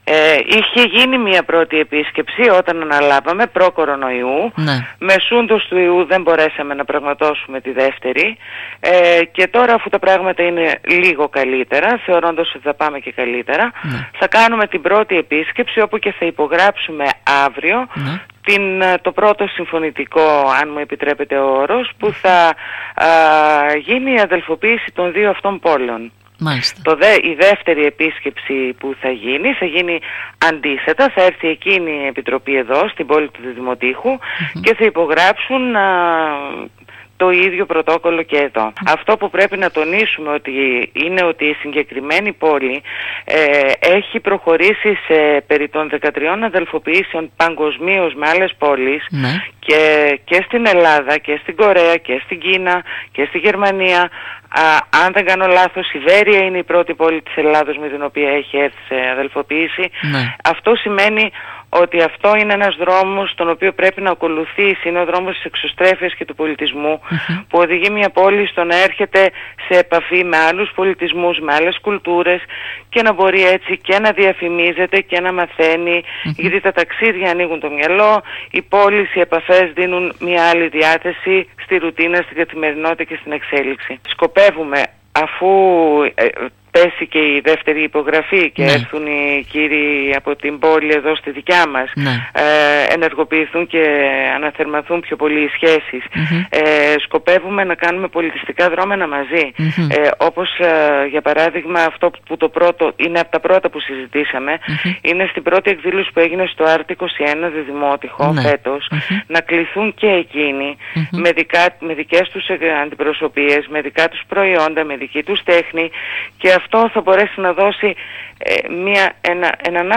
Σε δηλώσεις της στην ΕΡΤ Ορεστιάδας η Αντιδήμαρχος Ανάπτυξης και Αντιπρόεδρος του Ευγενίδειου Πολιτιστικού Ιδρύματος Στέλλα Θεοδοσίου τόνισε ότι αυτό είναι ένα εφαλτήριο για το Διδυμότειχο ένας δρόμος που πρέπει να ακολουθείς αυτός της εξωστρέφειας και του πολιτισμού» διευκρινίζοντας πως αν οι υγειονομικές συνθήκες το επιτρέψουν θα κληθούν να παραστούν  το ερχόμενο καλοκαίρι στις εκδηλώσεις Art 21 που με επιτυχία διοργανώθηκαν ώστε να μεταφέρουν στην κοινωνία μας τον δικό τους πολιτισμό.
Θεοδοσίου-Στέλλα-ΑΝΤΙΔΗΜΑΡΧΟΣ-ΔΙΔ.ΧΟΥ.mp3